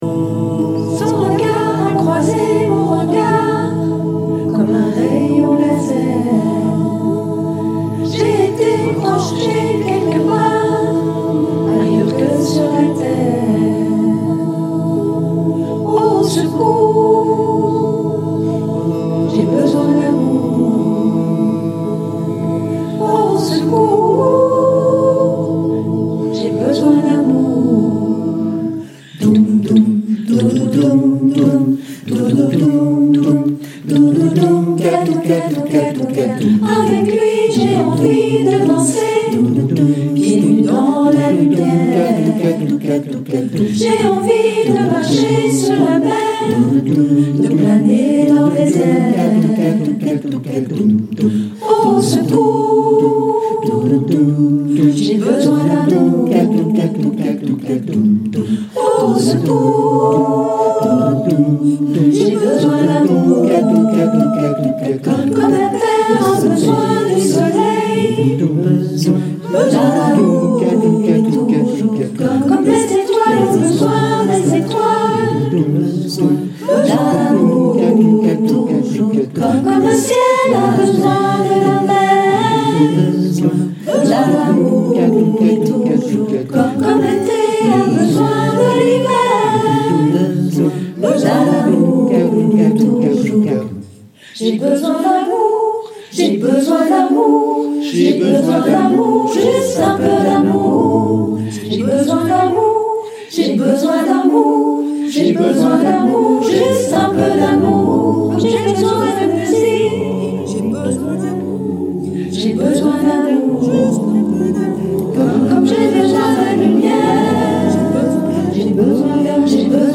Besoin-d-amour-Tutti-chante-chorale.mp3